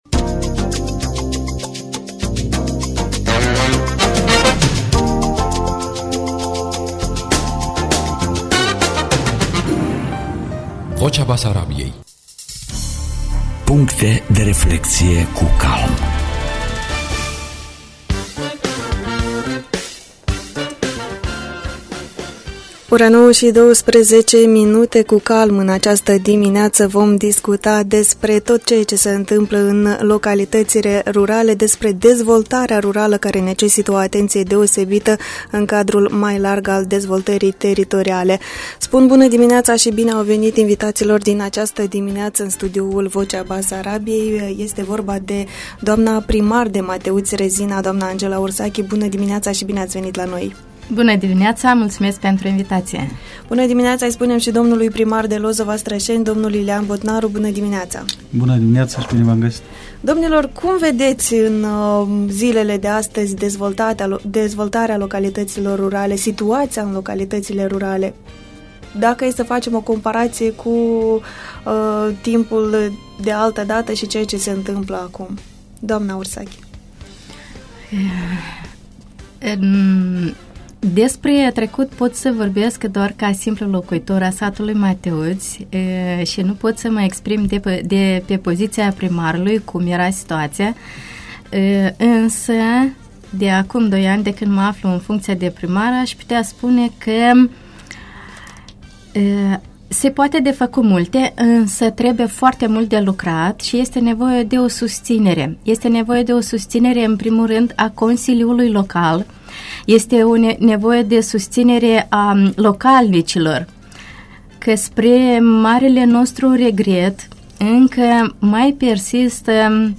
O țară fără sate nu poate exista, tot așa cum nu putem vorbi despre o dezvoltare națională fără o dezvoltare rurală. Aceste păreri au fost expuse de către primarul localității Lozova, raionul Strășeni, Lilian Botnaru și primarul localității Mateuți, raionul Rezina, Angela Ursachi, în cadul emisiunii Puncte de Reflecție cu CALM, la postul de radio Vocea Basarabiei.